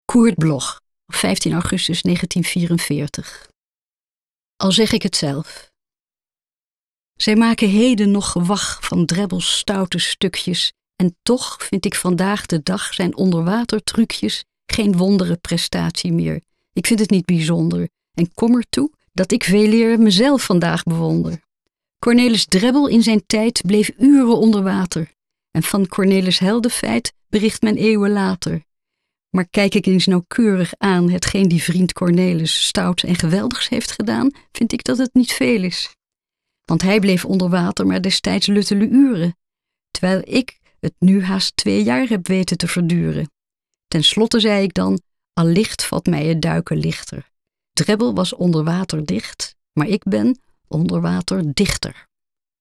Aufnahme: Studio Levalo, Amsterdam · Bearbeitung: Kristen & Schmidt, Wiesbaden